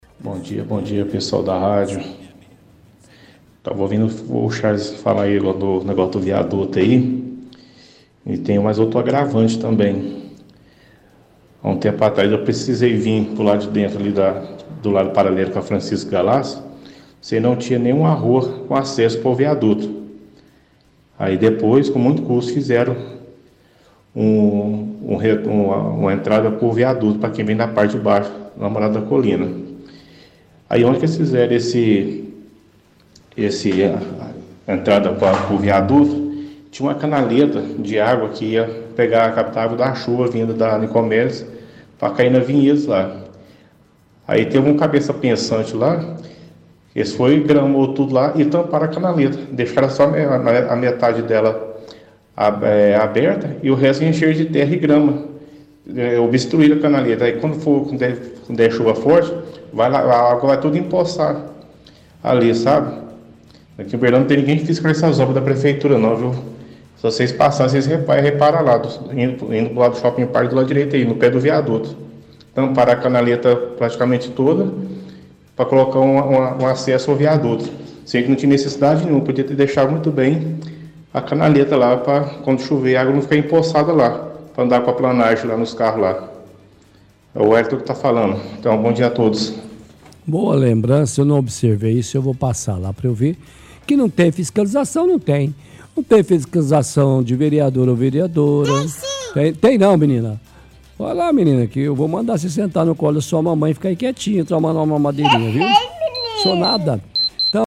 – Ouvinte diz que nenhuma rua dava acesso ao viaduto vindo pela Franscisco Galassi, depois de algum tempo resolveram, fala que tinha uma canaleta na Nicomedes, mas que foi tampada pela metade com terra e grama, mostra preocupação quando chegar a época de chuva, e também comenta que não tem ninguém para fiscalizar as obras da prefeitura.